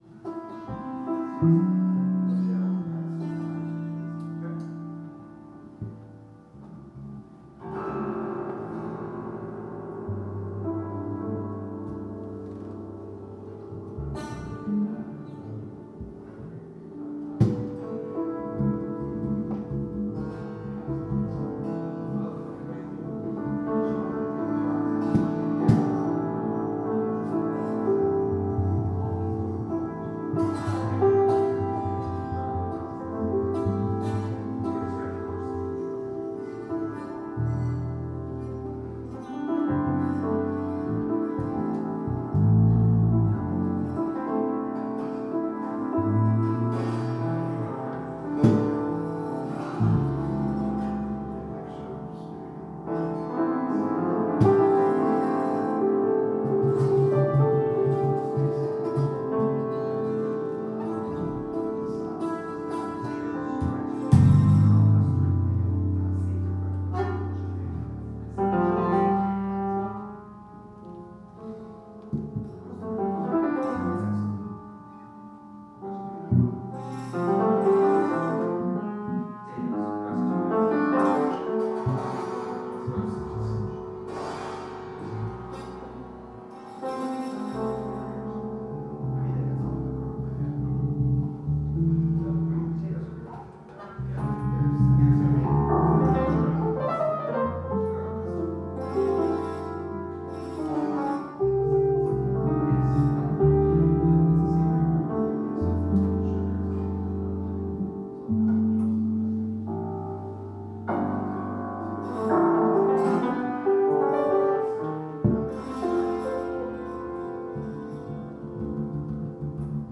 real-time prepared piano
electric bass live